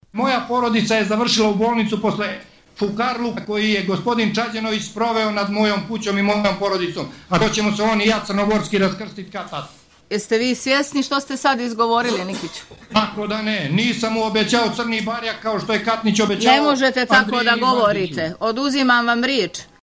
preko video linka obilovalo verbalnim sukobom